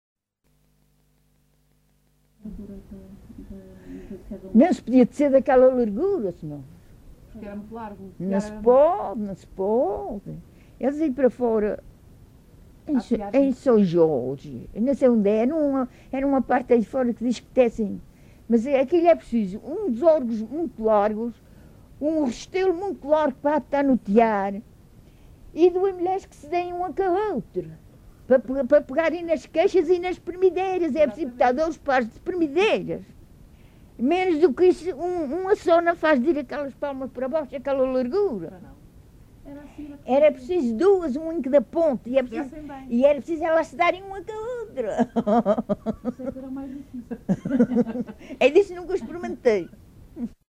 LocalidadeCosta do Lajedo (Lajes das Flores, Horta)